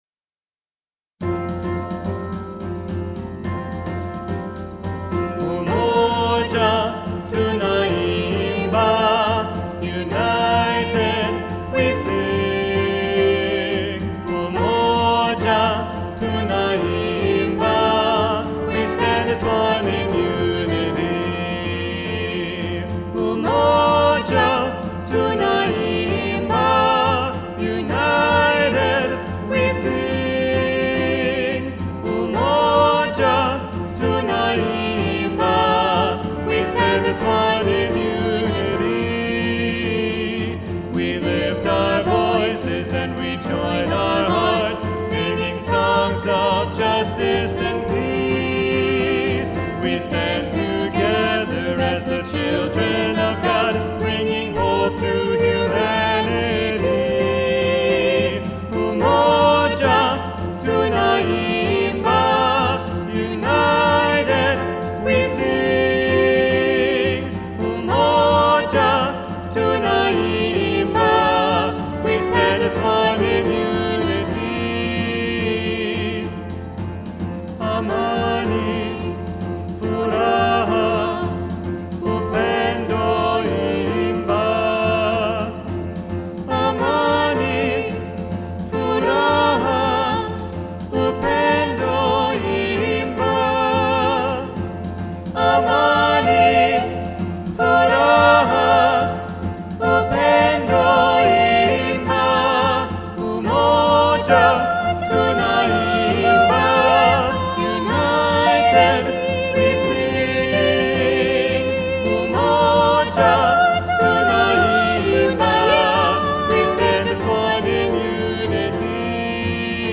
3-Part Mixed – Part 2b Muted